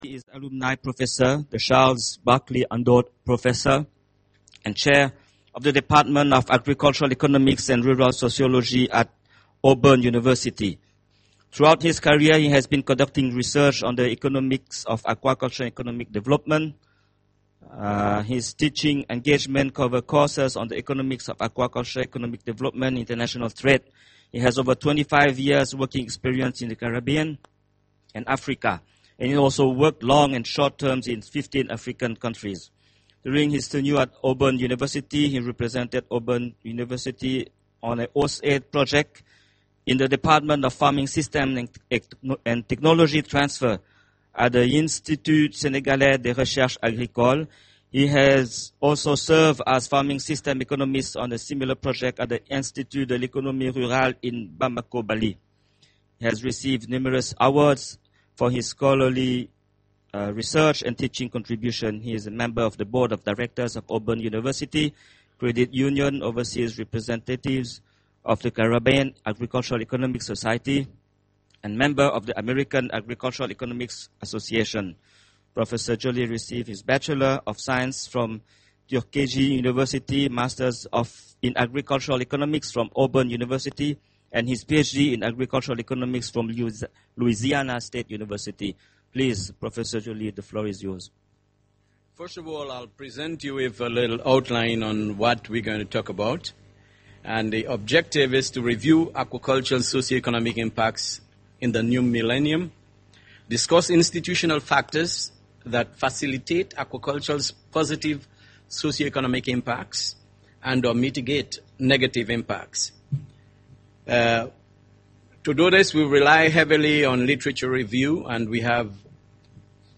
Presentation on the role of enabling policies and partnerships on aquaculture for socio-economic growth